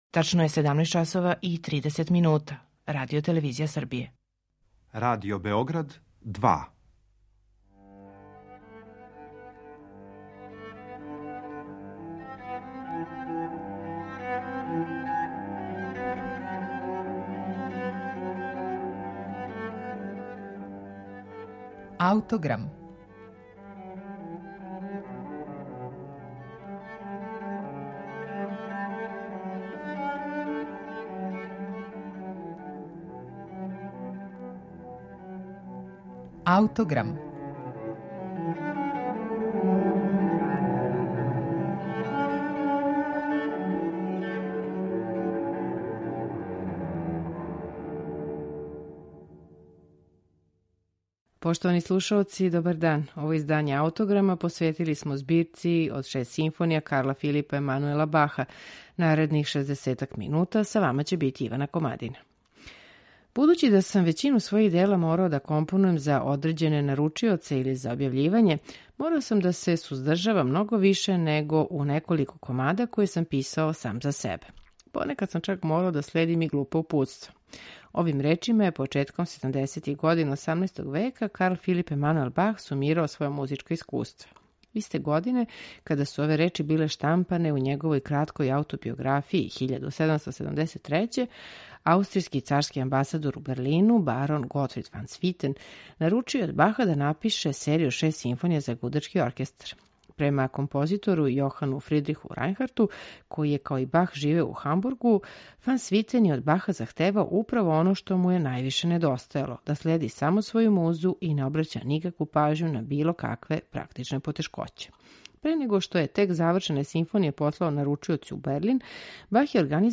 1773. године аустријски царски амбасадор у Берлину, барон Готфрид фан Свитен, наручио је од Карла Филипа Емануела Баха да напише серију од шест симфонија за гудачки окрестар.